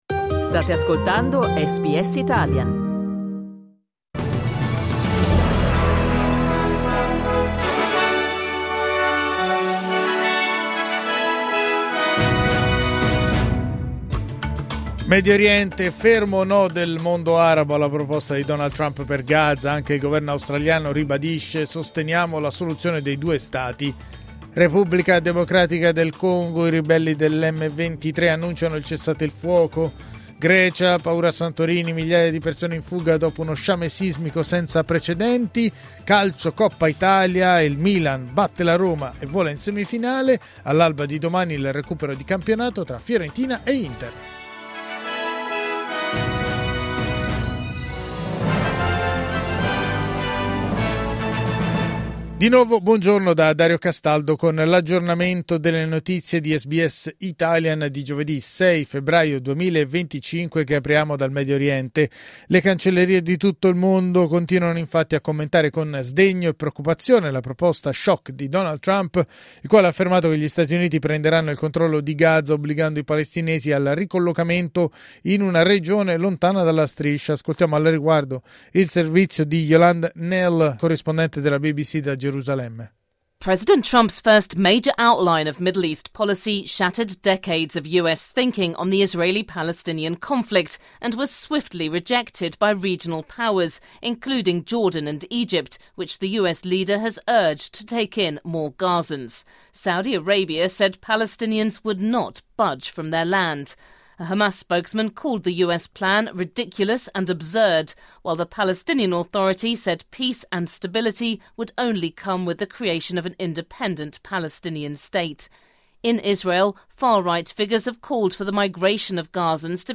News flash giovedì 6 febbraio 2025